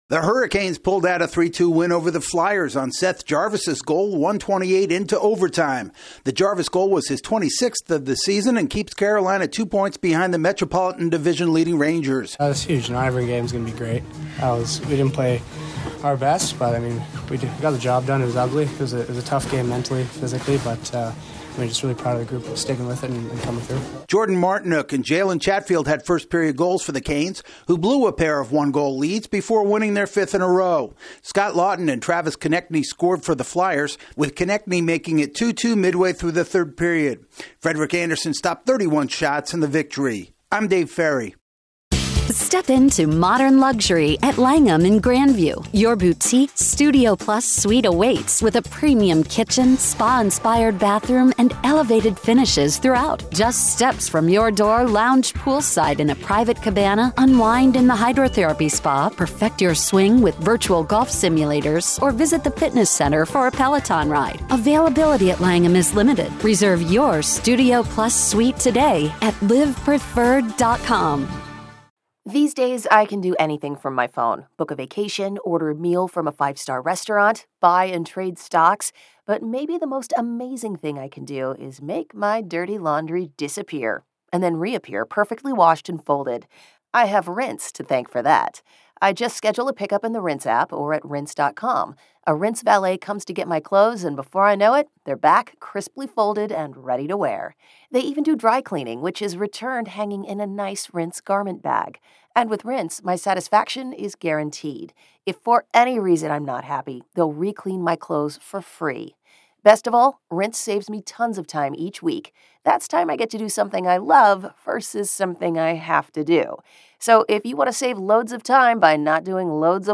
The Hurricanes pick up a big win over the Flyers in a potential first-round preview. AP correspondent